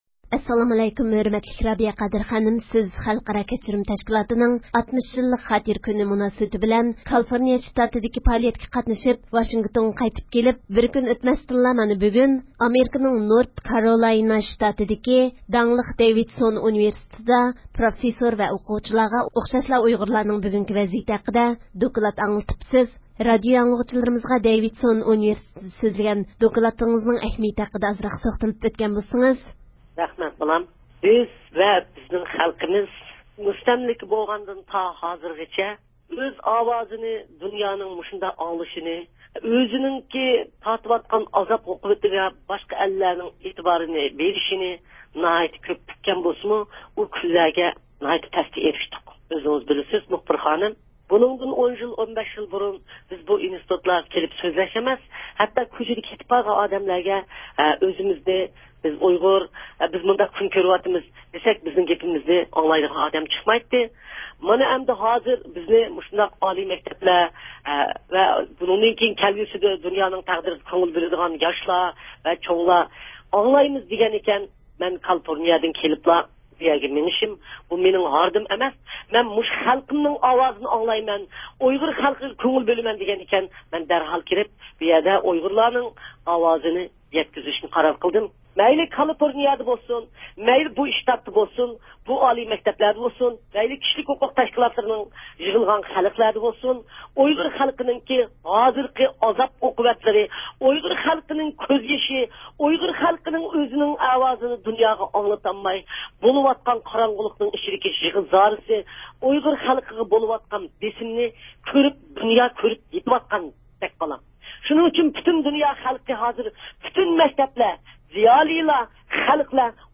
رابىيە قادىر خانىم دوكلاتىدا نۇقتىلىق ھالدا ، خىتاي دائىرىلىرىنىڭ نۆۋەتتە ئۇيغۇرلارغا قاراتقان سىياسىي بېسىملىرى ھەققىدە توختالغان. بىز رابىيە خانىمنىڭ دوكلاتىنىڭ مەزمۇنلىرى توغرىسىدا تولۇق مەلۇمات ئېلىش ئۈچۈن ، ئۇنى تېلېفۇن ئارقىلىق زىيارەت قىلدۇق.
يۇقىرىدىكى ئاۋاز ئۇلىنىشىدىن، رابىيە خانىم بىلەن ئۆتكۈزگەن سۆھبەتنىڭ تەپسىلاتىنى دىققىتىڭلارغا سۇنىمىز.